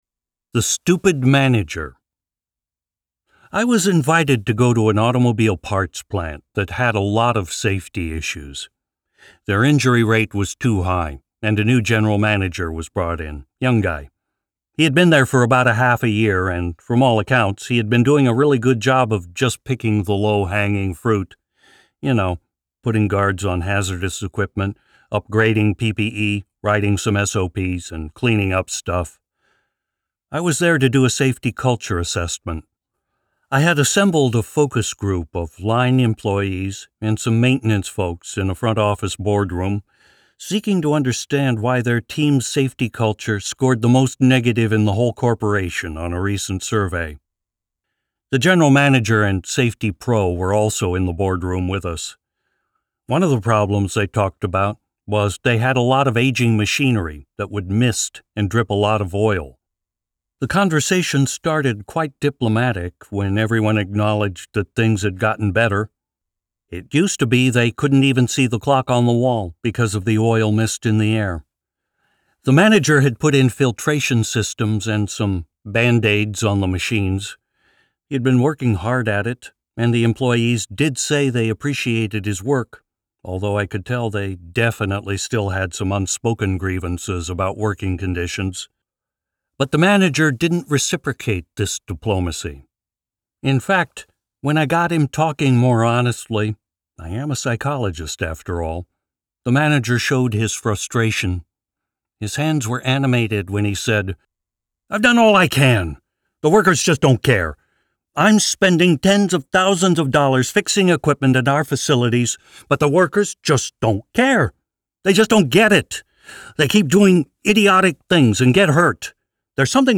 Audio Book (Amazon)